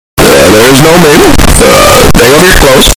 Obama(loud)
obama-loud.mp3